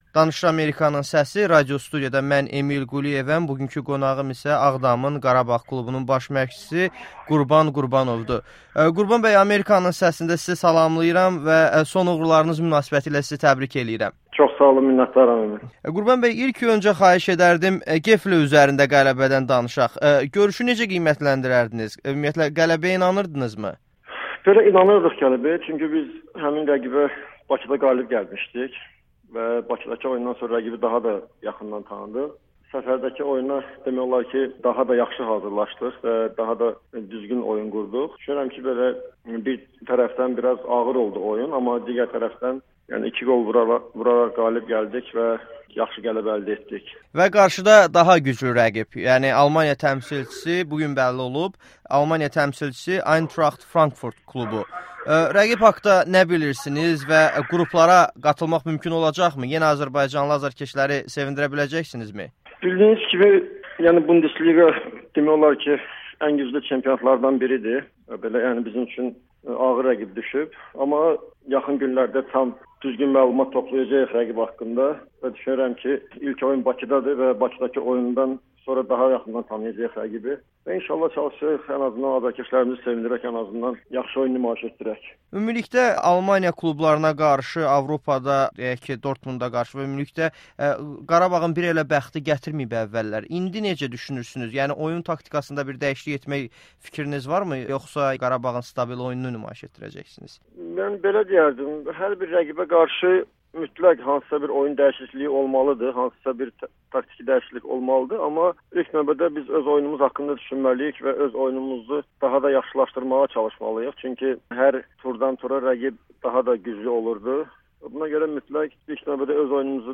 Ağdamın “Qarabağ” klubunun baş məşqçisi Amerikanın Səsinə eksklüziv müsahibə verdi